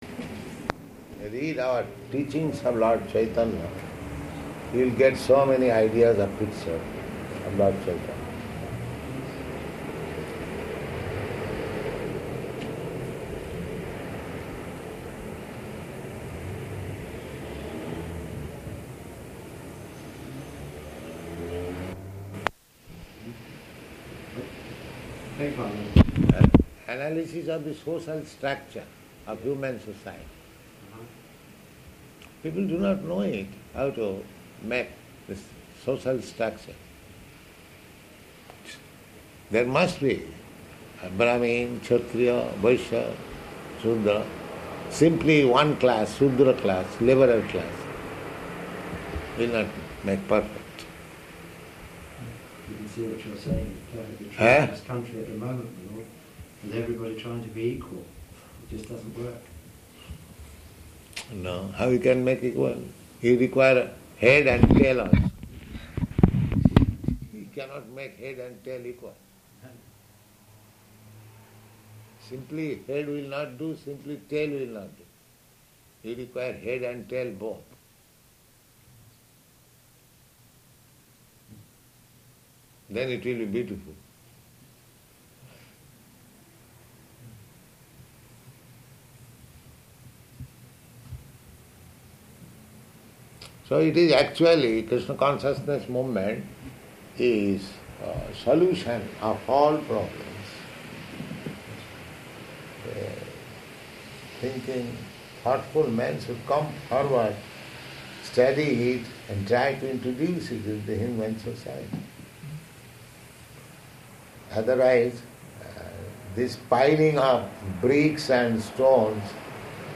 Room Conversation
Room Conversation --:-- --:-- Type: Conversation Dated: August 8th 1972 Location: London Audio file: 720808R1.LON.mp3 Prabhupāda: Read our Teachings of Lord Caitanya.